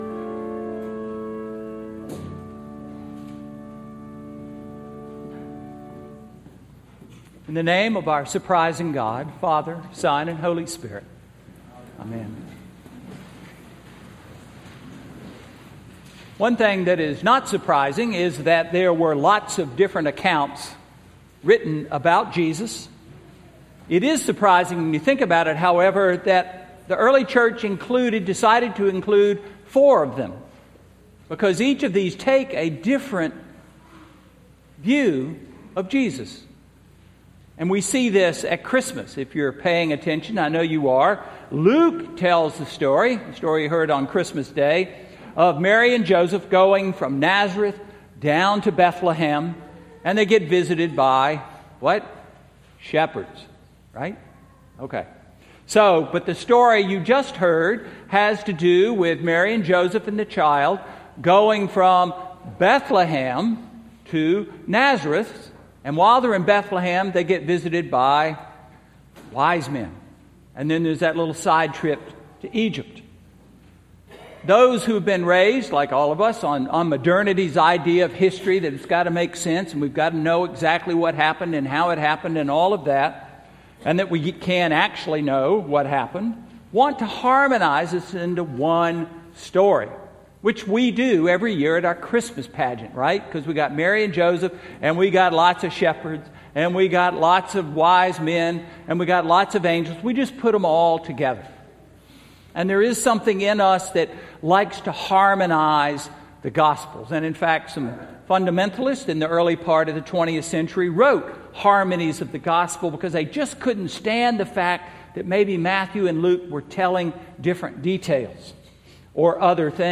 Sermon–January 5, 2014